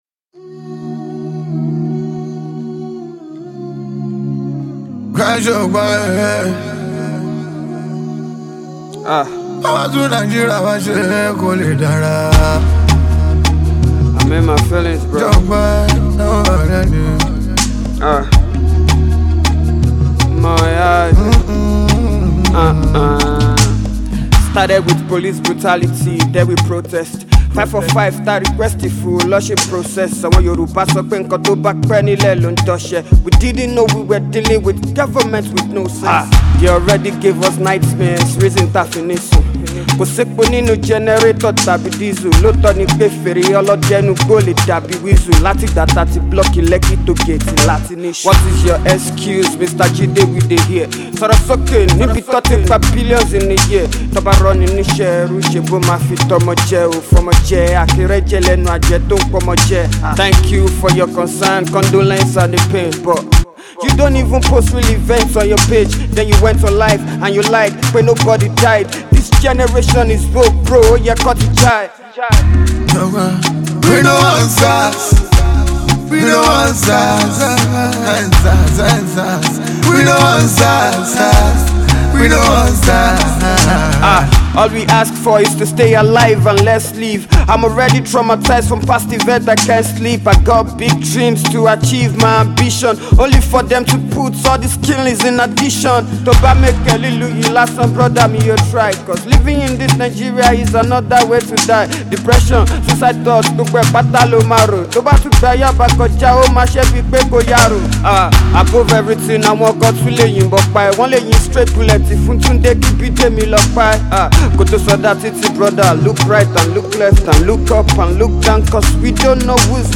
Nigerian rap artiste